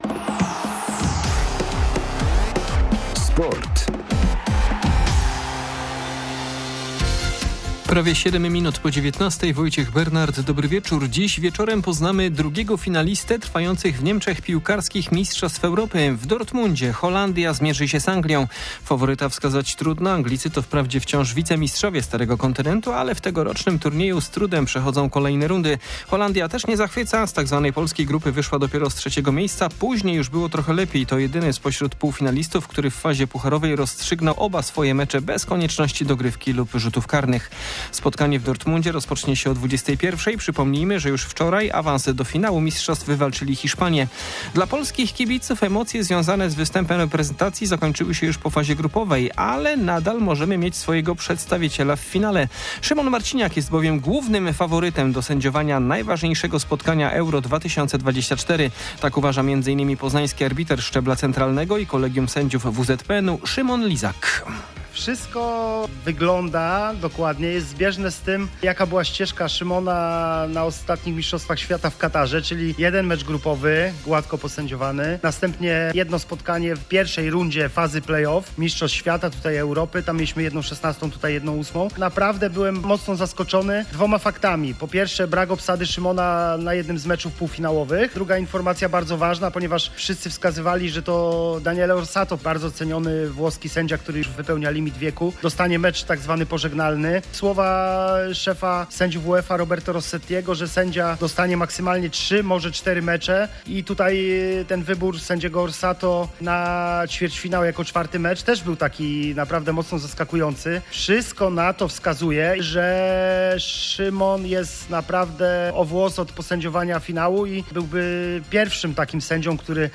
10.07.2024 SERWIS SPORTOWY GODZ. 19:05